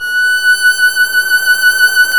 Index of /90_sSampleCDs/Roland L-CD702/VOL-1/STR_Violin 1 vb/STR_Vln1 Warm vb
STR VLN MT0T.wav